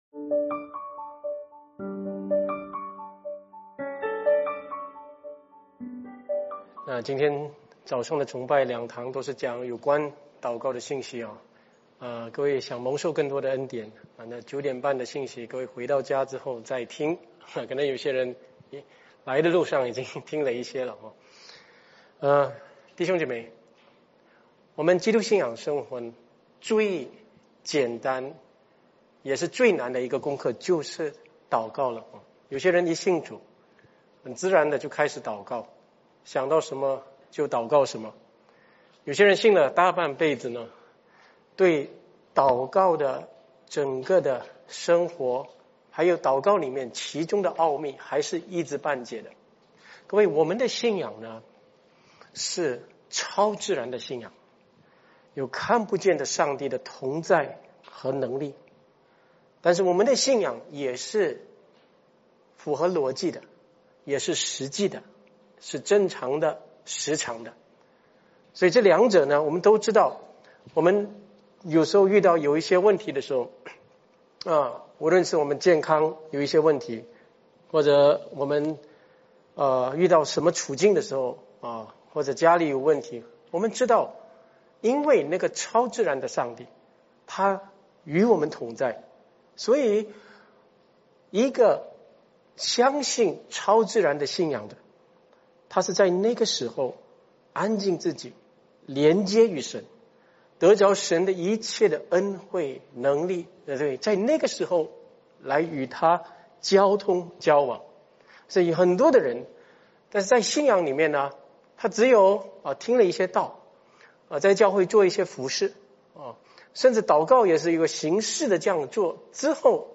几位牧师：关于祷告的讲道